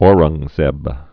(ôrəng-zĕb) 1618-1707.